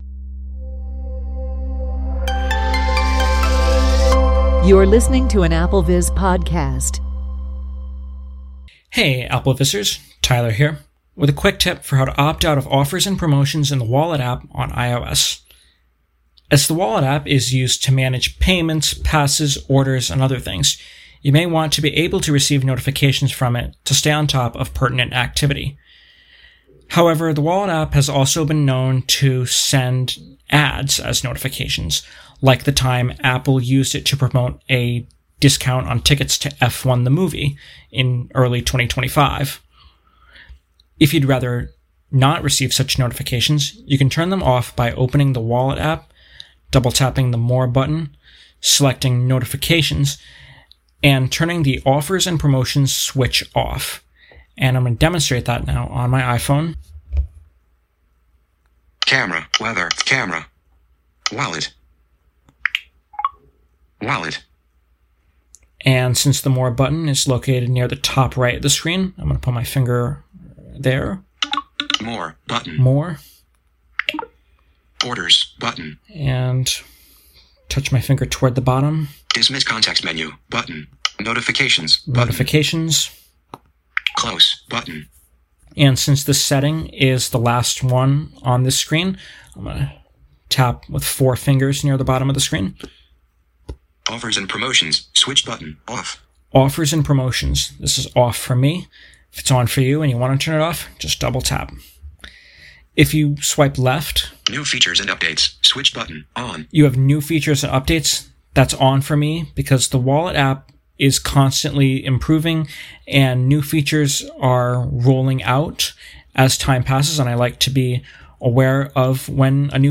VoiceOver : camera weather camera wallet wallet